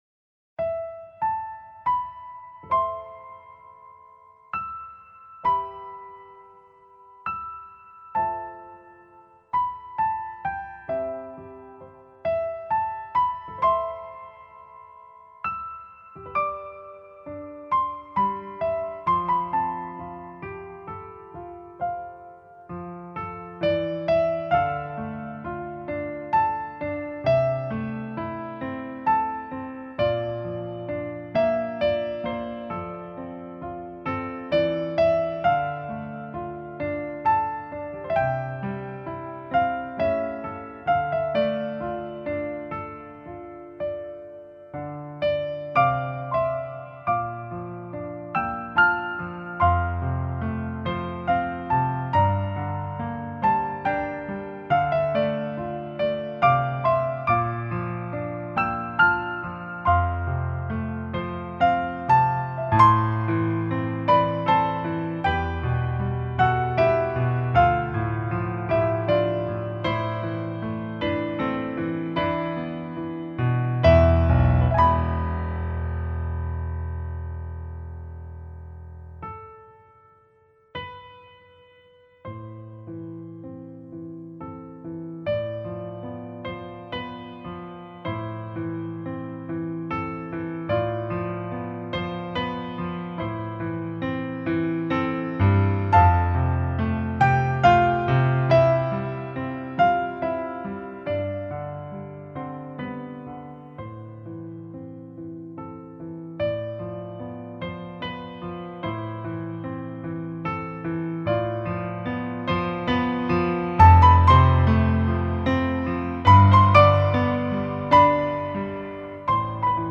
命运（钢琴版）